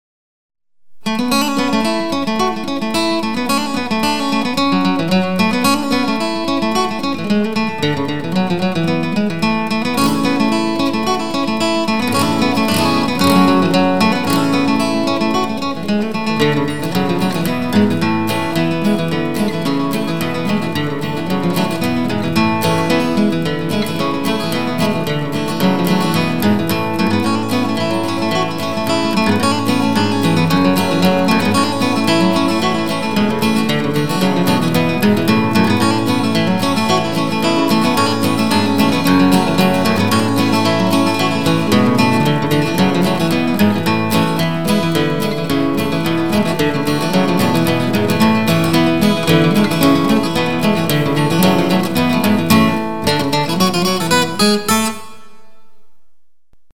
Irish Folk
Gesang, Gitarre, Bouzouki
Geige, Flöte, Gesang
Akkordeon, Gesang
Bass, Gitarre, Mandoline
Schlagzeug, Bodhran